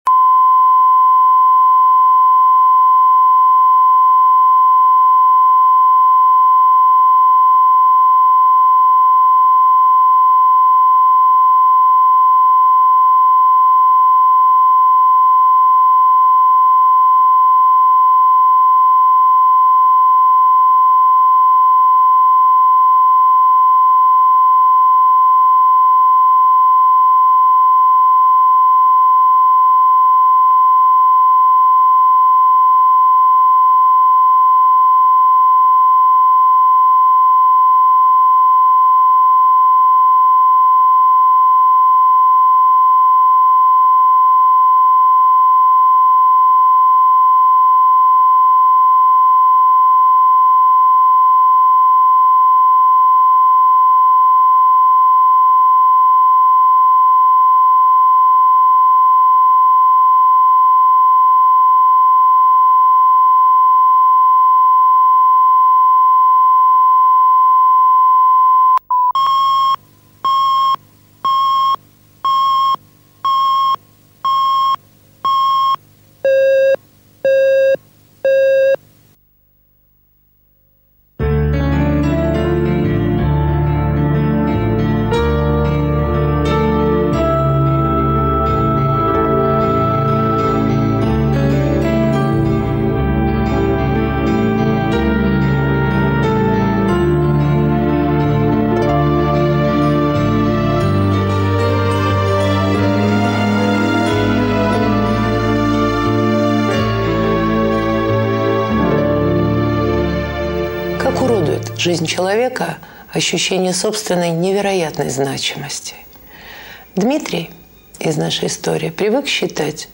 Аудиокнига Письма счастья | Библиотека аудиокниг